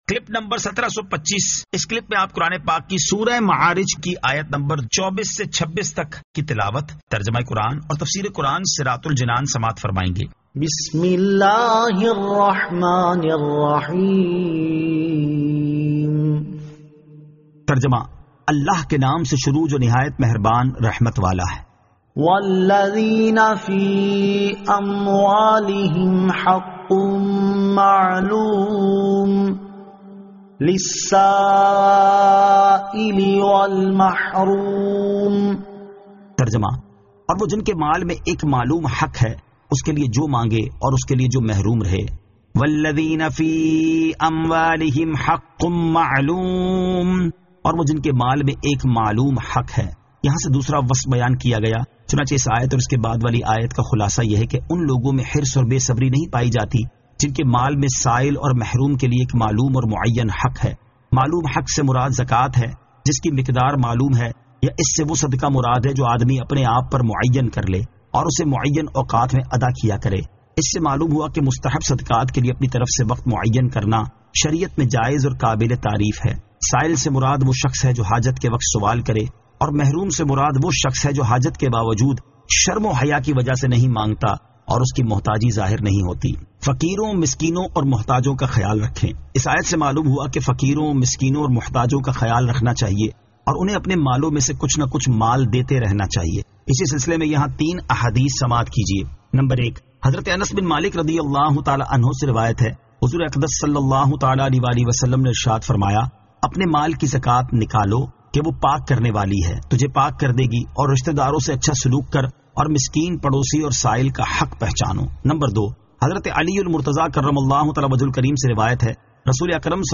Surah Al-Ma'arij 24 To 26 Tilawat , Tarjama , Tafseer